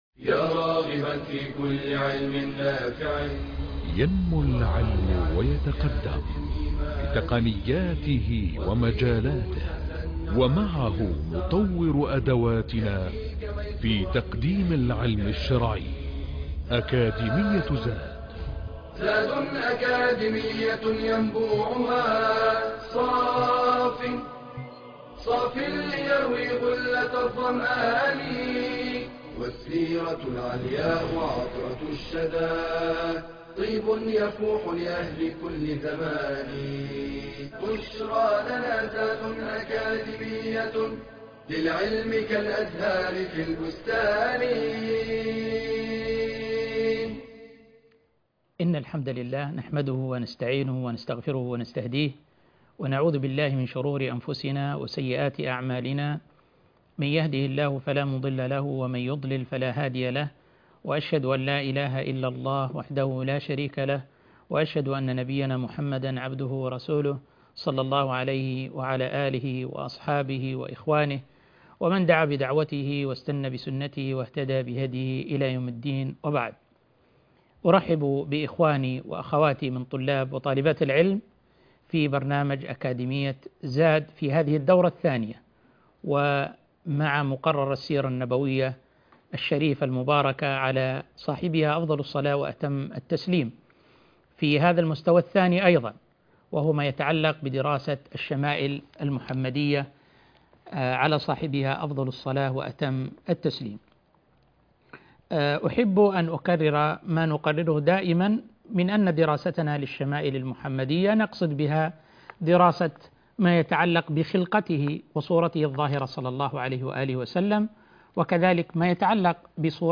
المحاضرة الثالثة عشر - إدام النبي صلى الله عليه وسلم